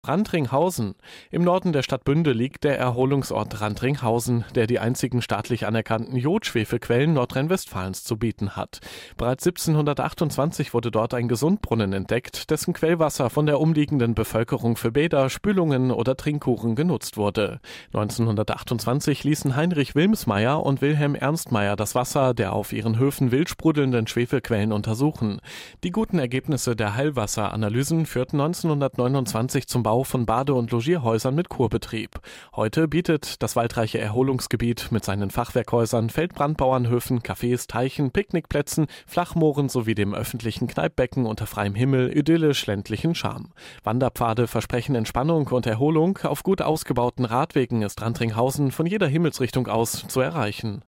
Audioguide Bauernbad-Radelroute Bünde
Mit dem Audioguide zur Bauernbad-Themenroute in Bünde erhalten Sie gesprochene Informationen zu den Besonderheiten entlang der Route.